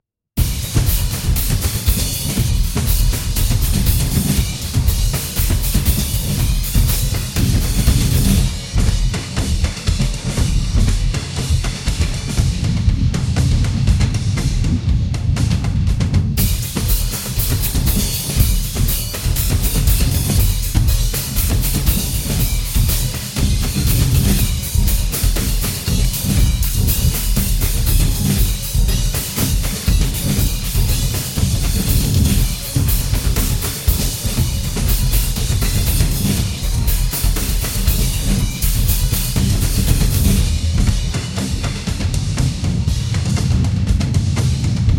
1 ファイル 703.92 KB ダウンロード 「激しい」カテゴリーの関連記事 情熱のドラマー 3月 24